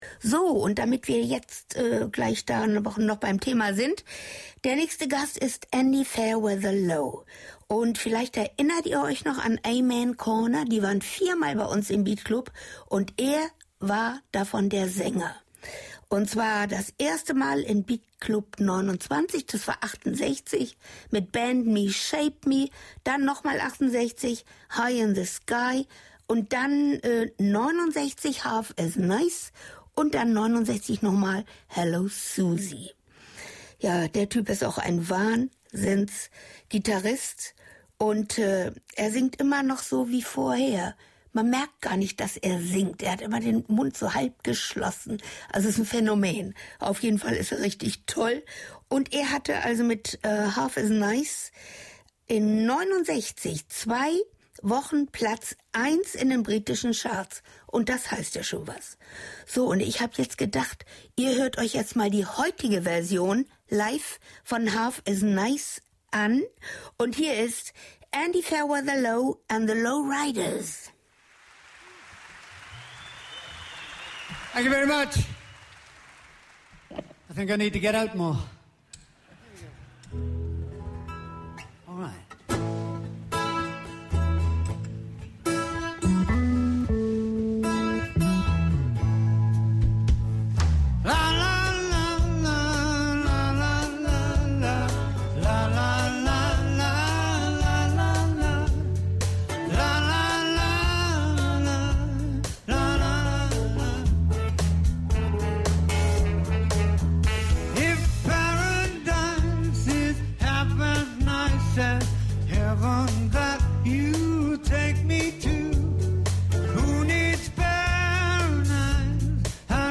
war wahre Meisterkunst an der Gitarre.